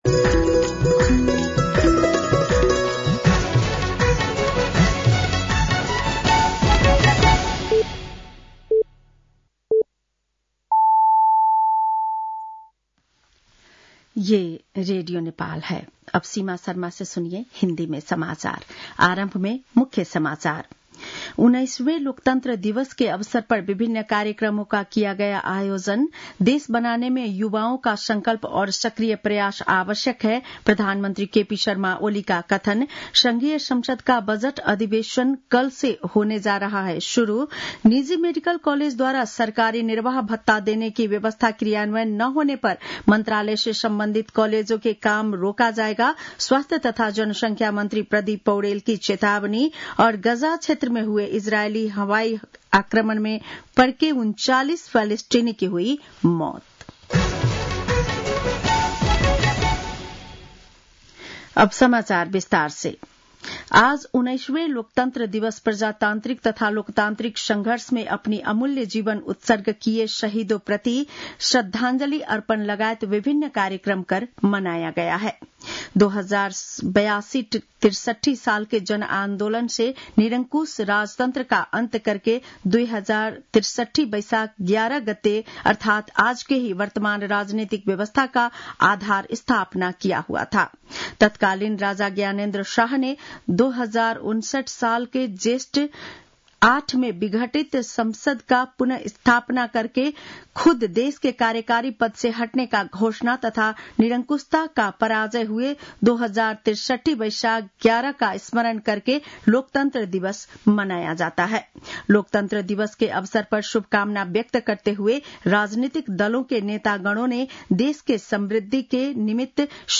बेलुकी १० बजेको हिन्दी समाचार : ११ वैशाख , २०८२